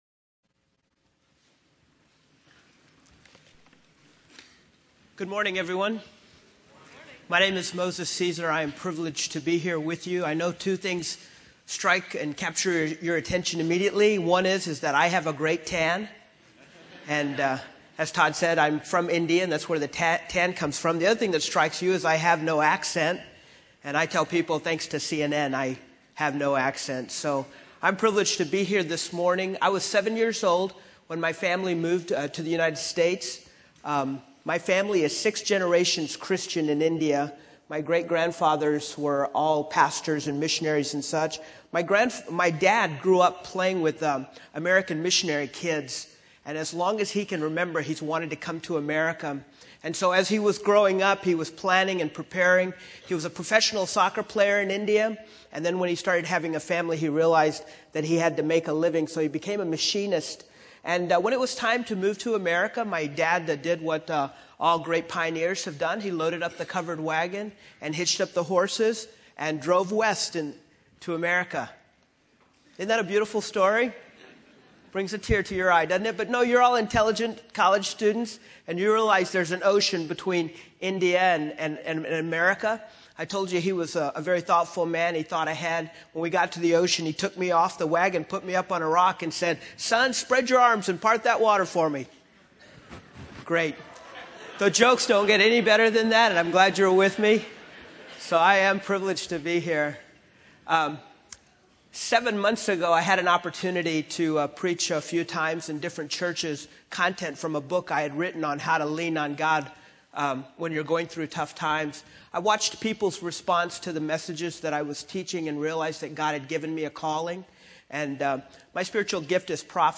Address: "Looking for Direction"